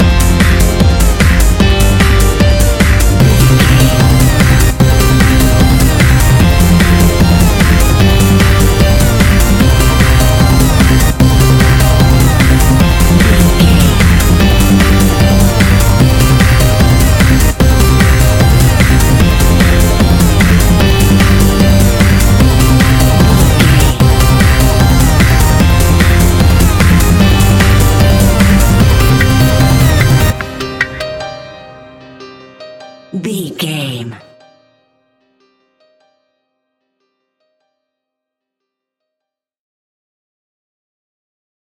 Aeolian/Minor
Fast
driving
energetic
futuristic
hypnotic
industrial
drum machine
synthesiser
piano
acid house
uptempo
synth leads
synth bass